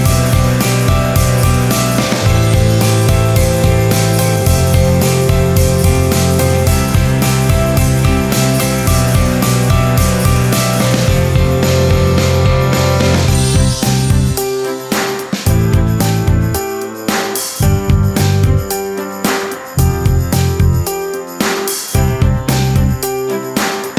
With Intro Voiceover Pop (2010s) 3:17 Buy £1.50